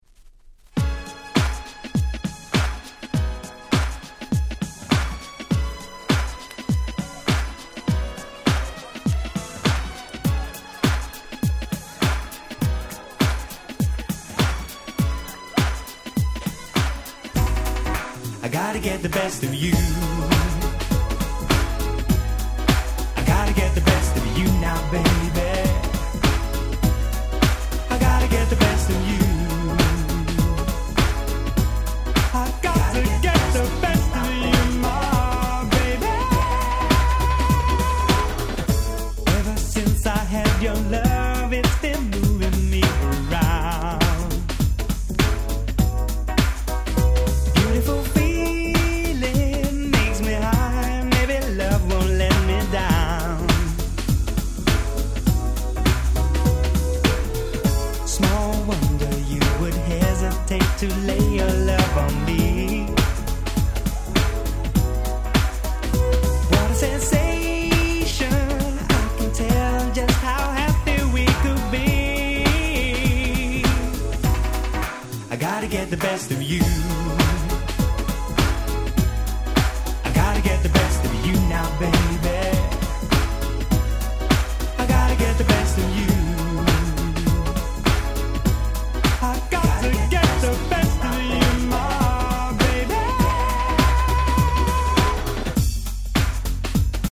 90' Nice UK Soul/R&B !!
ハネたBeatに爽やかでキャッチーな歌が最高！！
鉄板の人気UK R&Bです！